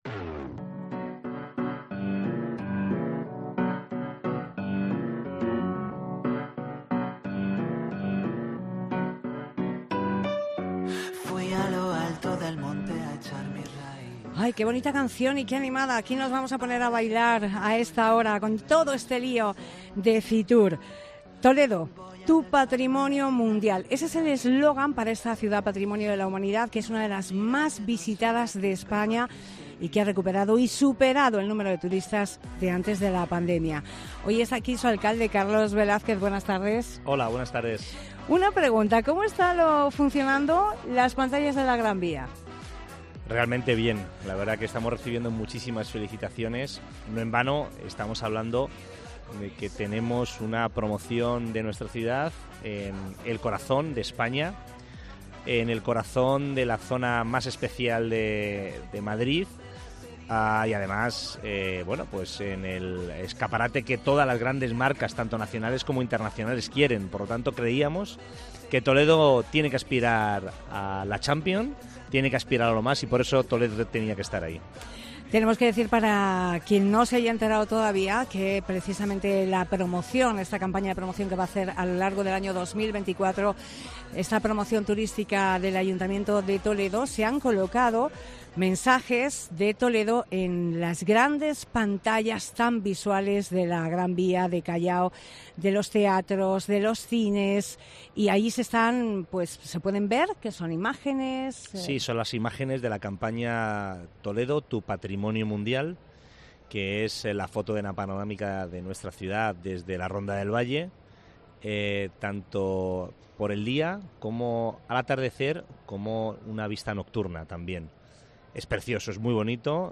FITUR | Entrevista a Carlos Velázquez, alcalde de Toledo